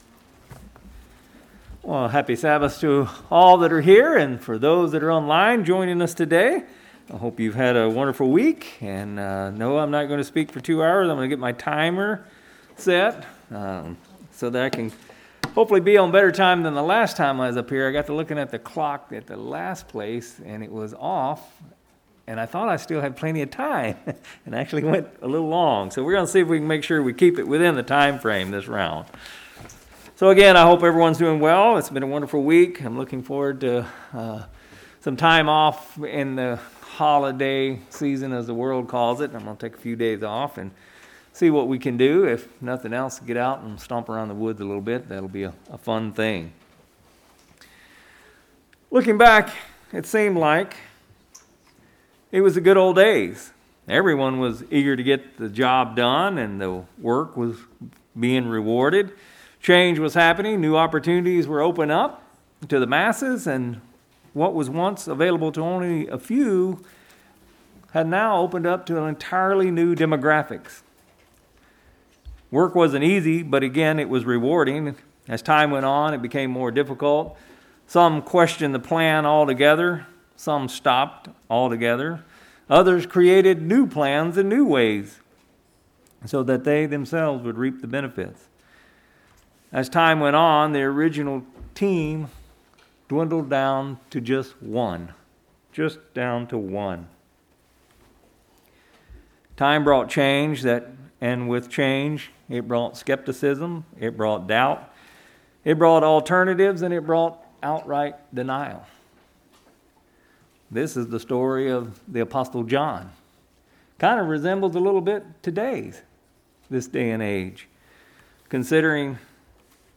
Sermons
Given in Salem, OR